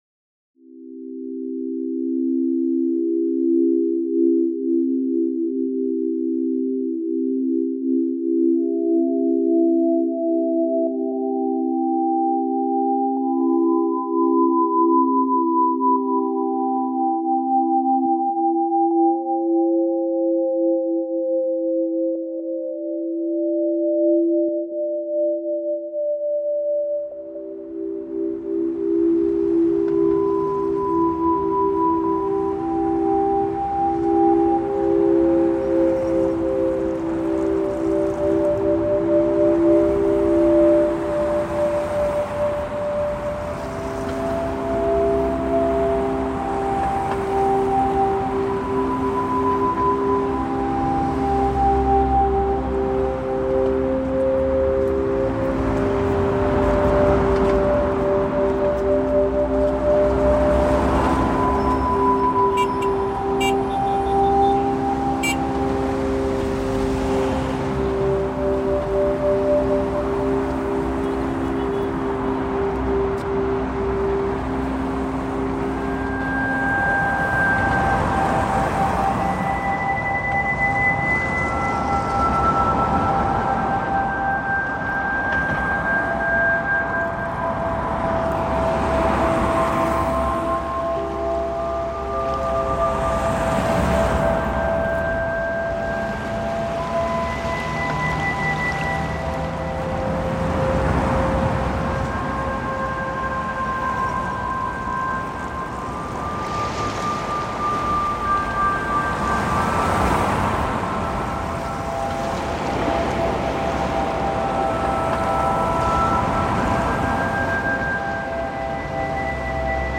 Moscow traffic reimagined